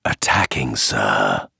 Voice Ripped from Star Wars Galactic Battlegrounds, Strangely enough the Voice Actor did both units in the same voice so you can mix these around with some of the quotes being replaced here and there.